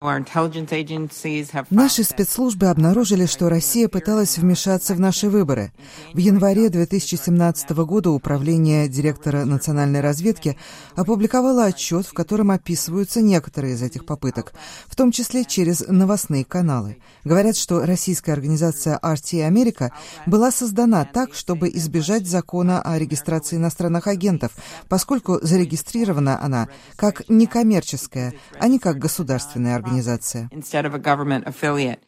В Вашингтоне в Сенатском комитете по юридическим делам состоялись слушания, посвященные Закону о регистрации иностранных агентов (FARA) и попыткам повлиять на выборы в США.